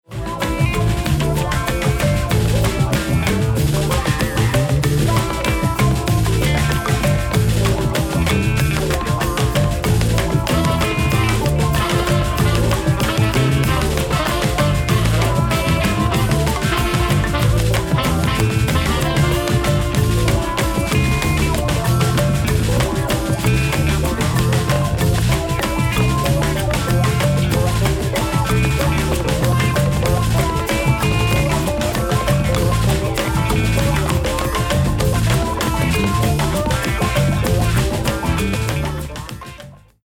Mixing Monster Folk Sample
Caution: Loud
Mixed (No Mastering)